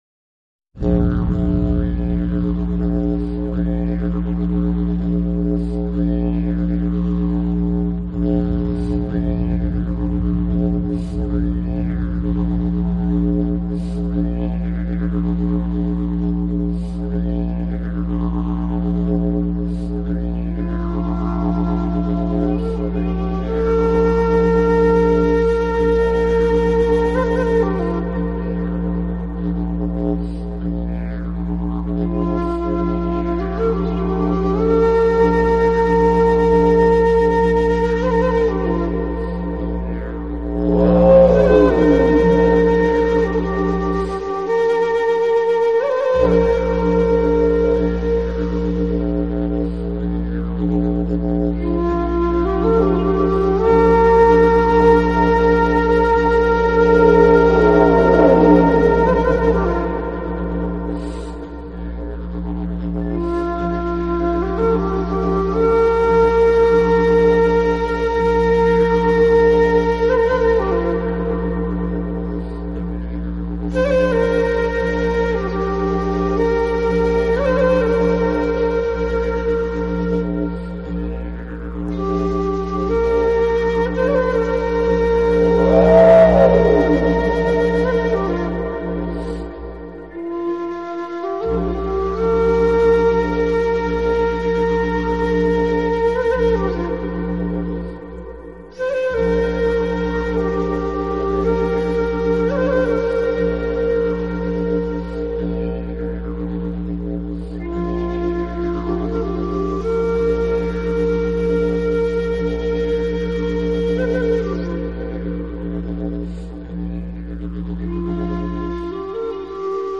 【纯音乐专辑】
藏笛吹响了，清远、嘹亮，自在、安恬。
藏笛的音色介乎于埙、笛之间；吹藏笛者是一位西藏的喇嘛。
作品几乎都是即兴而成——是随着心律的脉动而化生出来音律，因此犹如天籁。